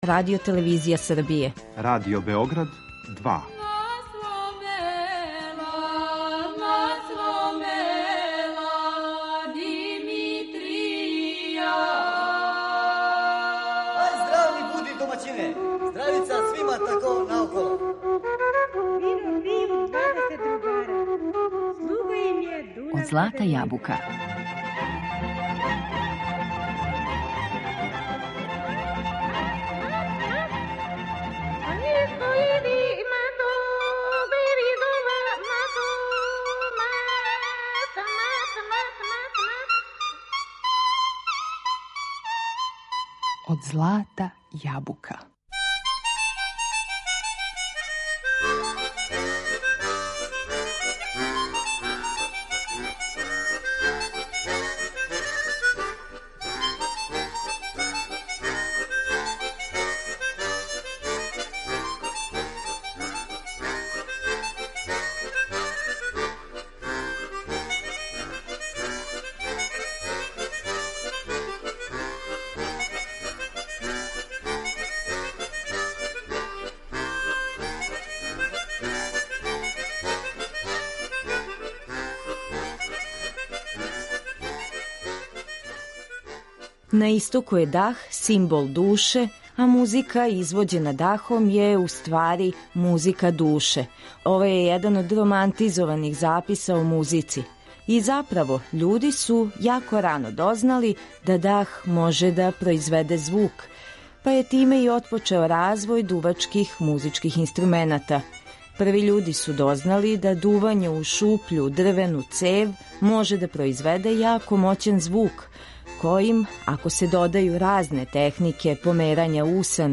Усна хармоника
Издвојили су се изврсни свирачи који су снимали трајне снимке за Радио Београд. Неке од њих слушамо у данашњем издању емисије Од злата јабука у којој се бавимо инструментом усна хармоника.